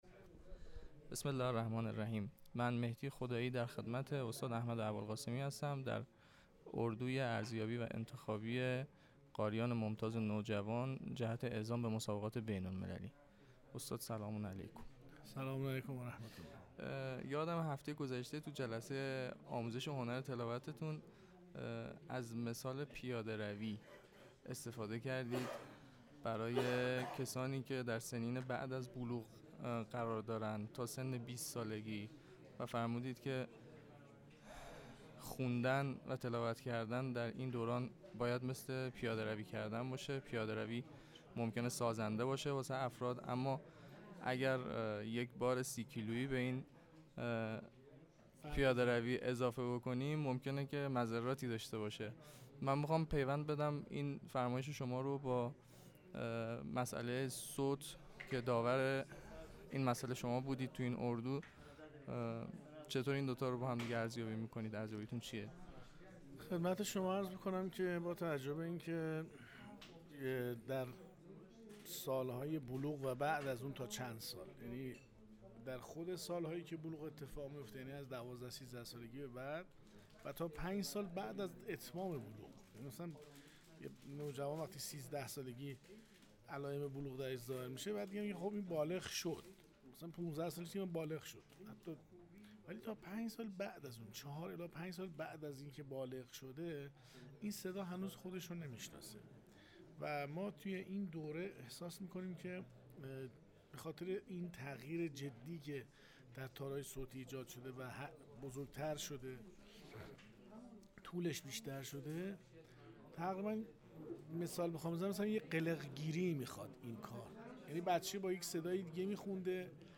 صوت مصاحبه